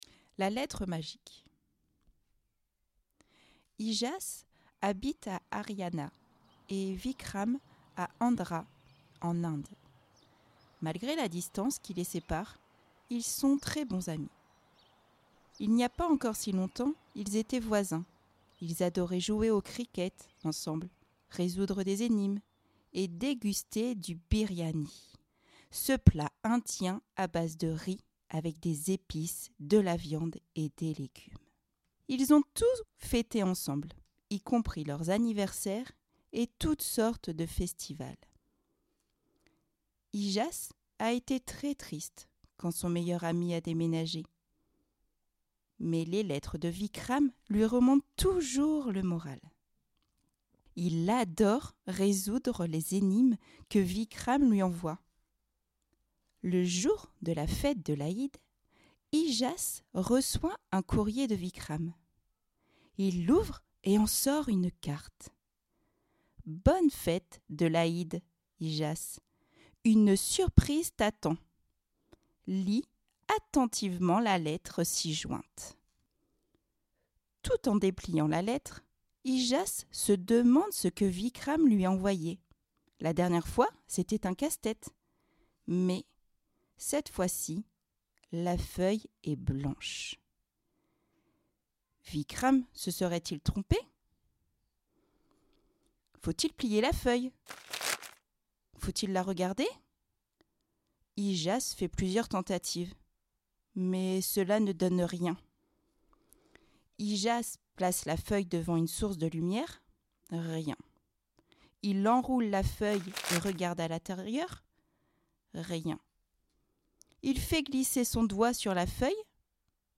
Lectures Audios – Centre Social Intercommunal La Maison du Chemin Rouge